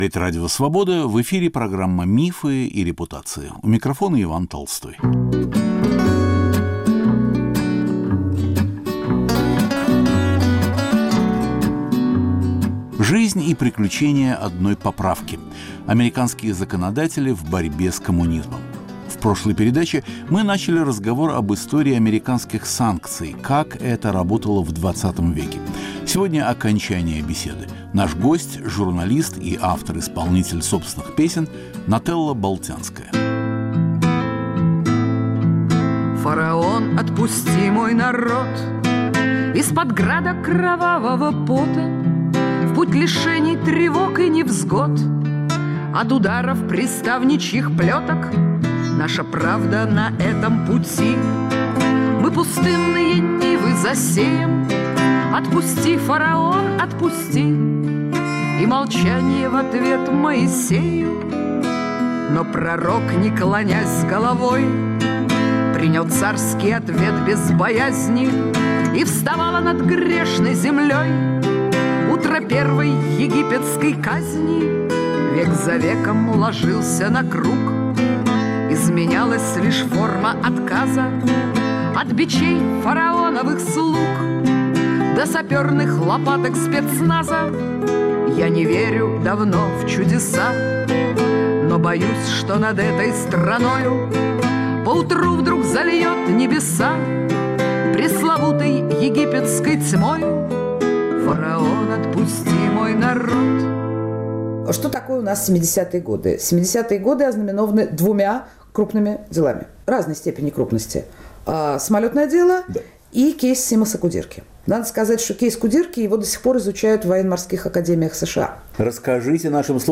Рассказывает (и поет) Нателла Болтянская.